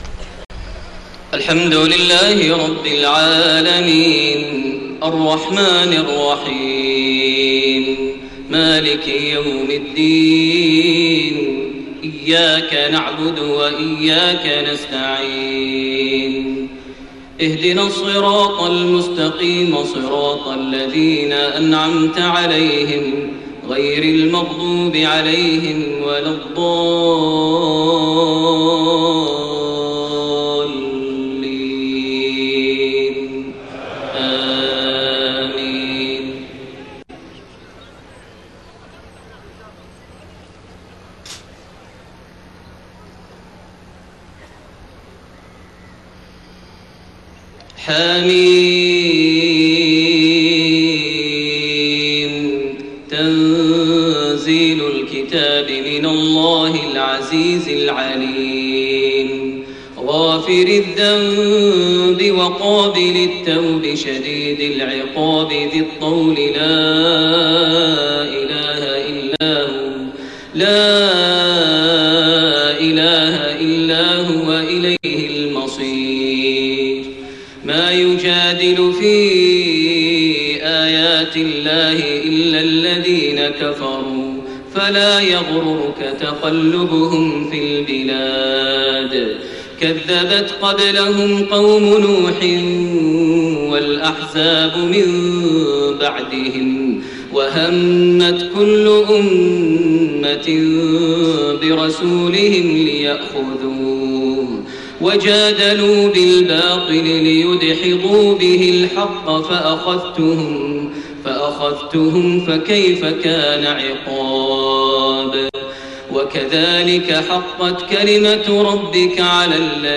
صلاة العشاء6-5-1431 من سورة غافر1-14 > 1431 هـ > الفروض - تلاوات ماهر المعيقلي